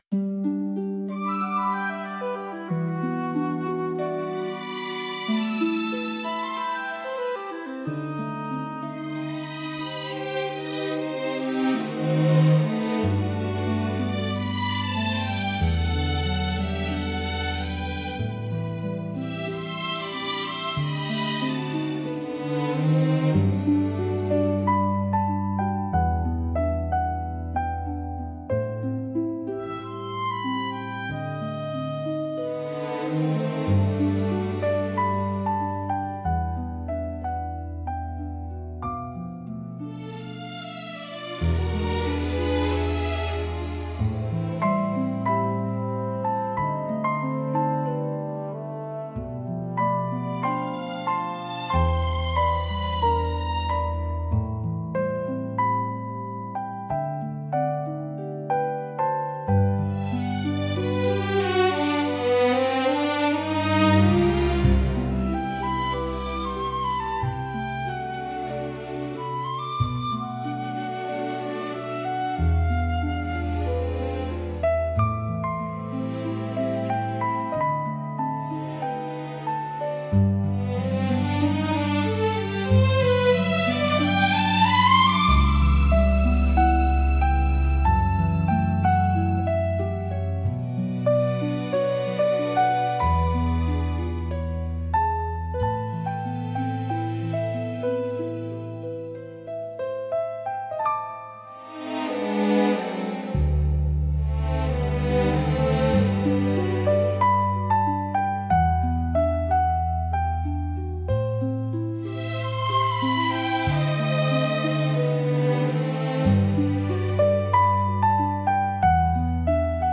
静かで清々しい空気感を演出しつつ、心に明るい希望や期待を芽生えさせる効果があります。